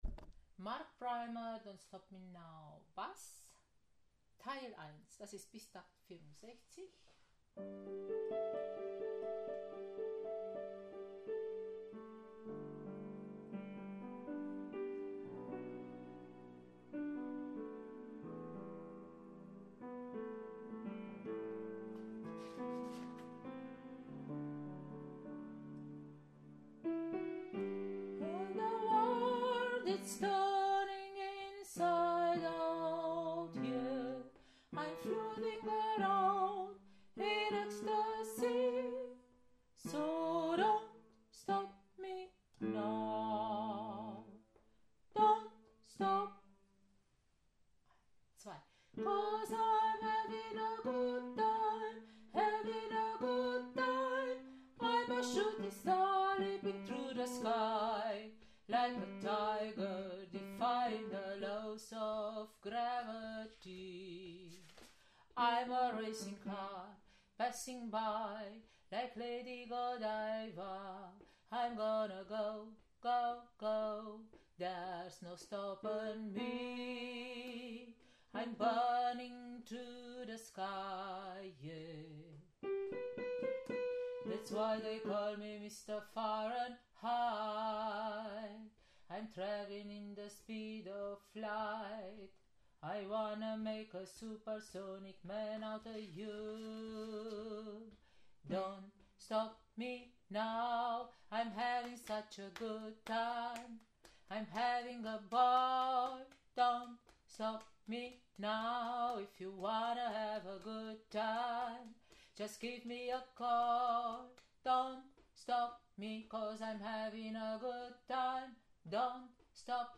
Don’t stop me now Bass Teil 1